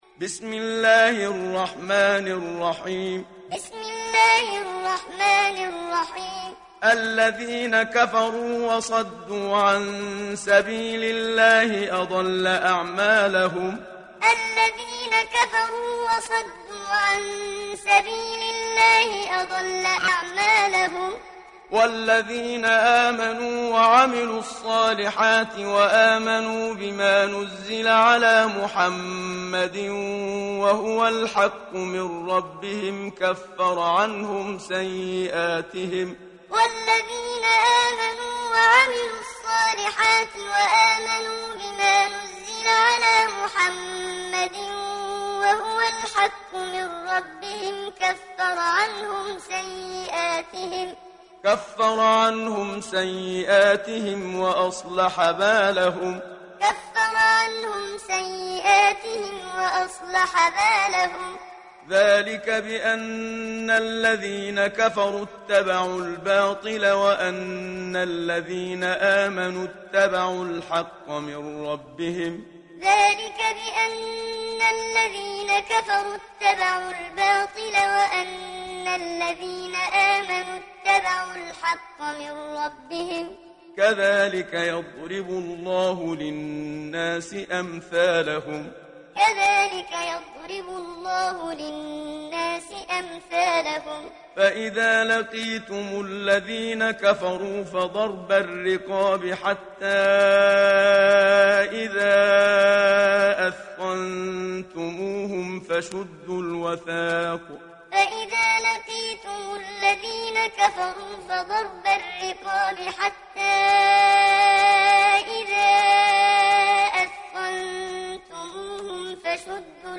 دانلود سوره محمد mp3 محمد صديق المنشاوي معلم روایت حفص از عاصم, قرآن را دانلود کنید و گوش کن mp3 ، لینک مستقیم کامل
دانلود سوره محمد محمد صديق المنشاوي معلم